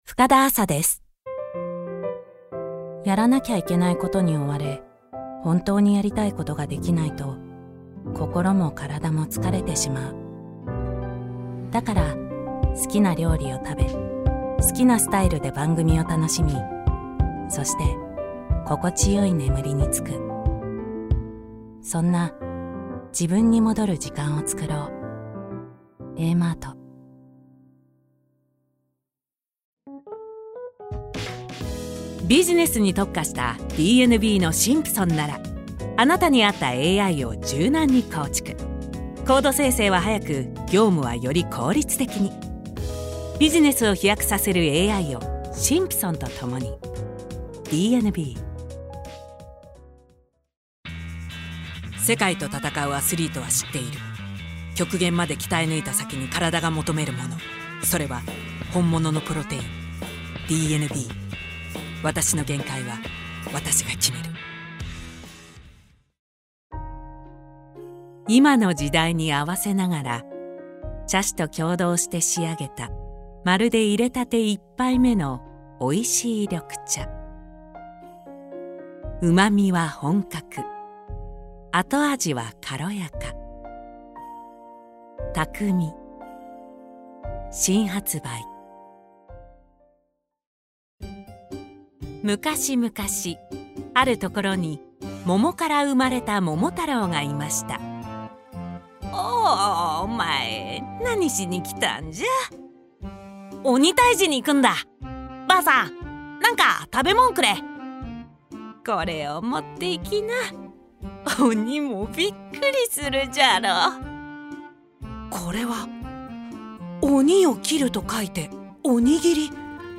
ボイスサンプル
• 音域：中音〜低音
• 声の特徴：クール、ナチュラル、さわやか
• CM